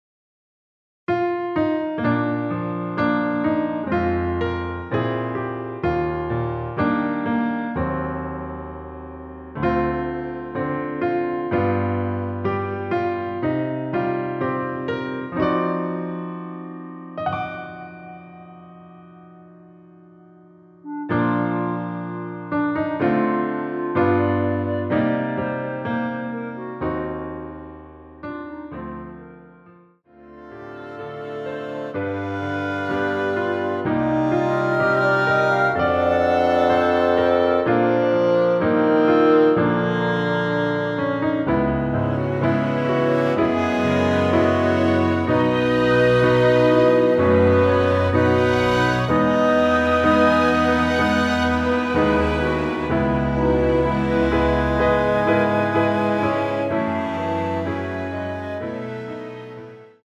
Bb
앞부분30초, 뒷부분30초씩 편집해서 올려 드리고 있습니다.